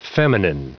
Prononciation du mot feminine en anglais (fichier audio)
Prononciation du mot : feminine